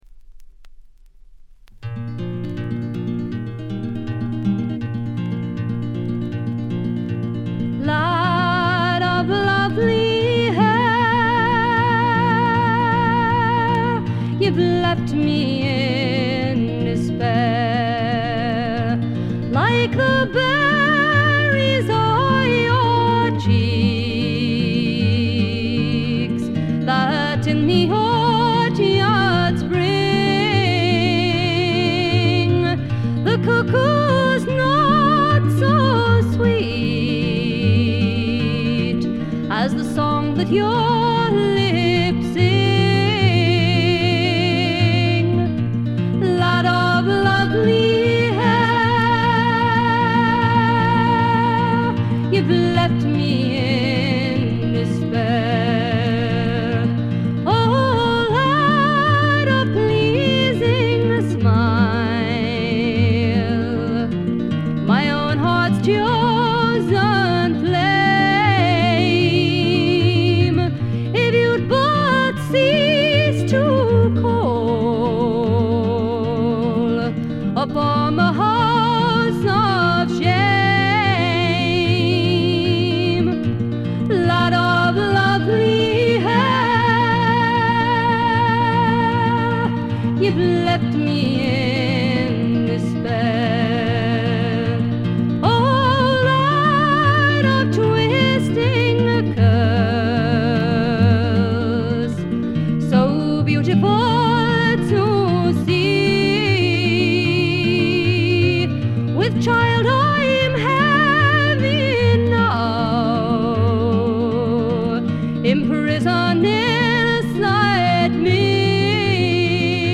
ホーム レコード：英国 フォーク / トラッド
わずかなバックグラウンドノイズ、チリプチ程度。
試聴曲は現品からの取り込み音源です。